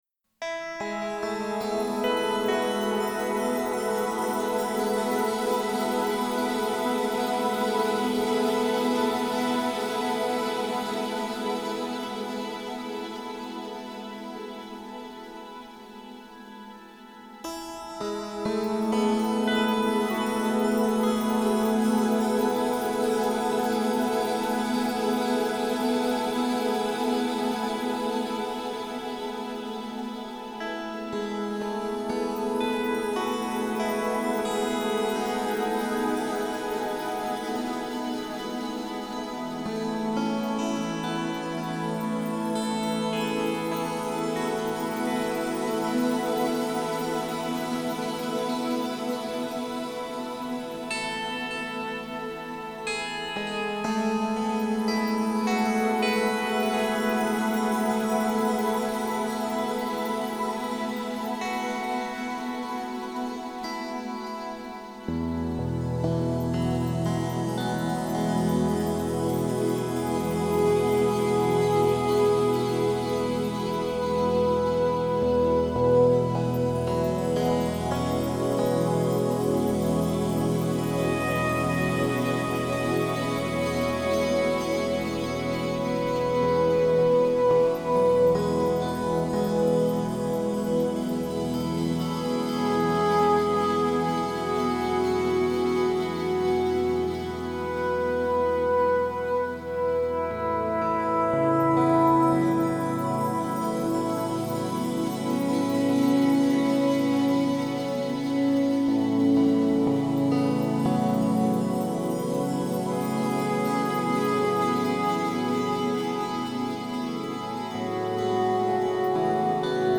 New age Нью эйдж Медитативная музыка